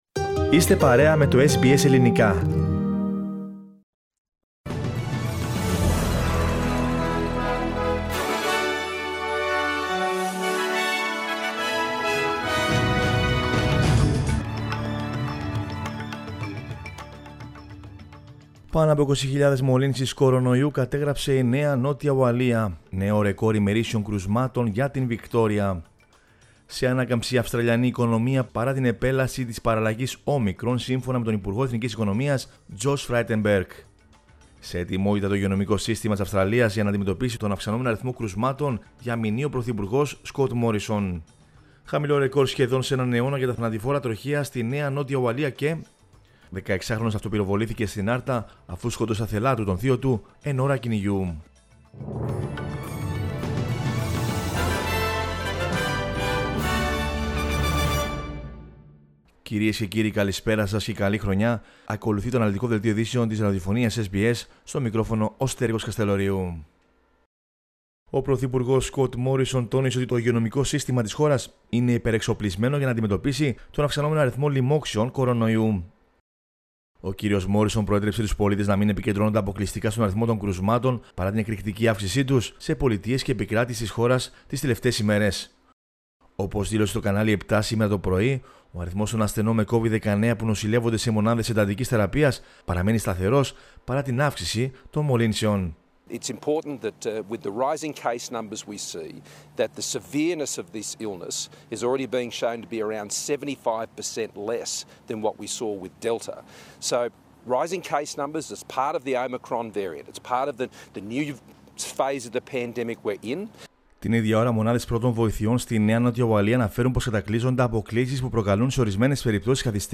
News in Greek from Australia, Greece, Cyprus and the world is the news bulletin of Monday 3 January 2022.